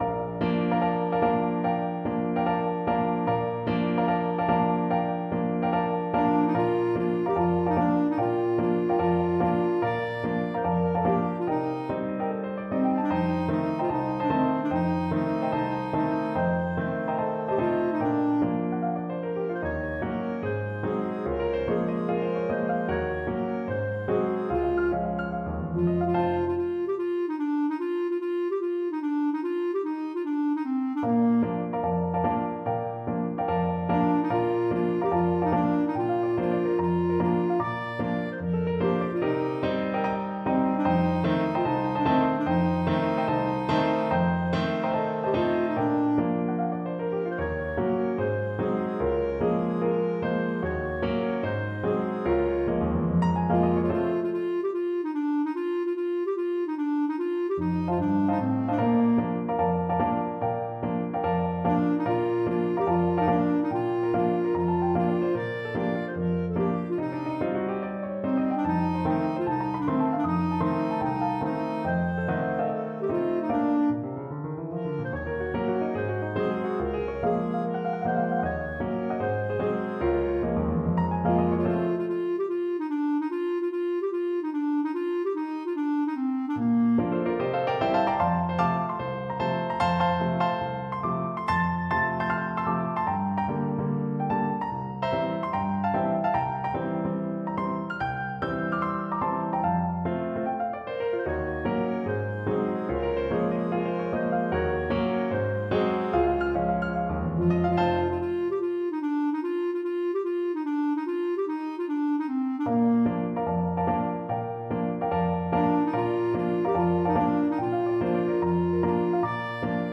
Ноты для фортепиано.
*.mid - МИДИ-файл для прослушивания нот.